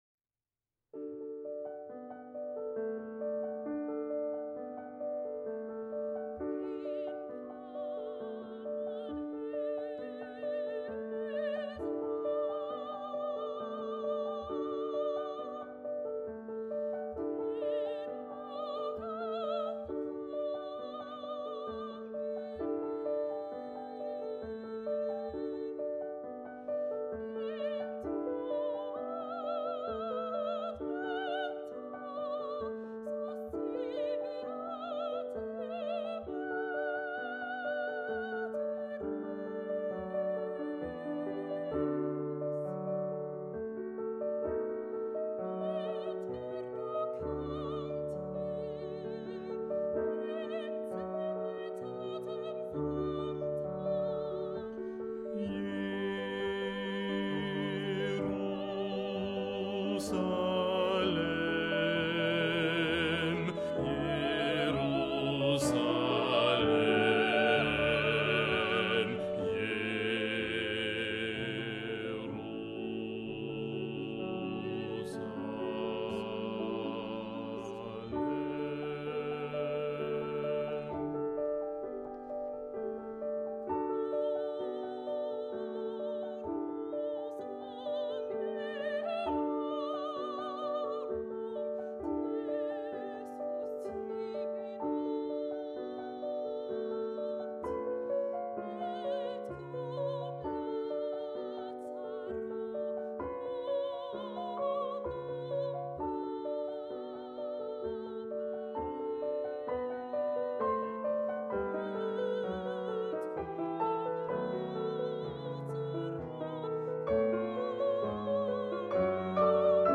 in paradisum basse 2 amplifiee